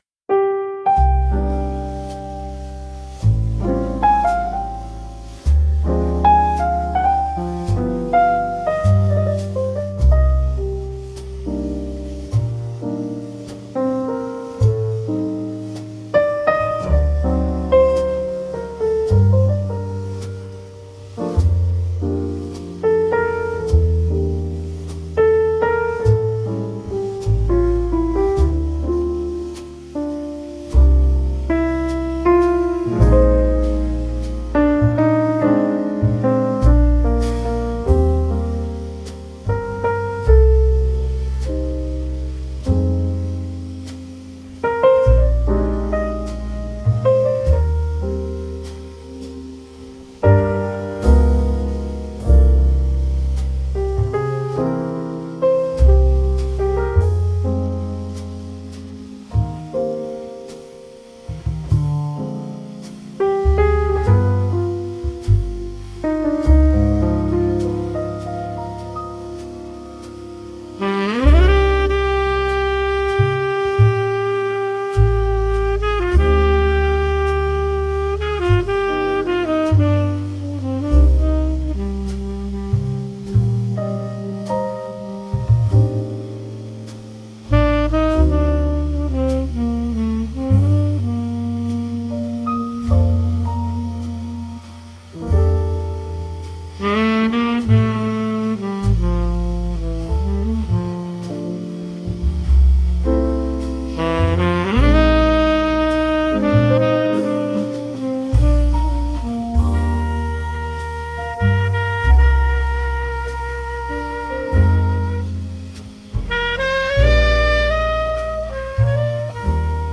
• Аранжировка: Generative Audio Workstation Suno Platform
• Жанр: Джаз
Через тонкие гармонии и воздушные ритмы музыка передаёт магию перехода дня в ночь, приглашая слушателя к созерцанию скрытых смыслов бытия. Это музыка‑медитация о бренности и красоте мига - одиночества наедине с вечностью.